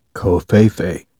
/ˈkōvˌfāfā/